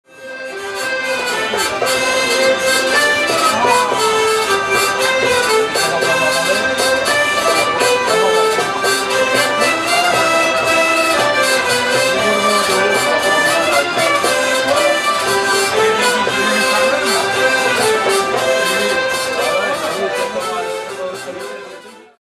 LA FÊTE DE LA VIELLE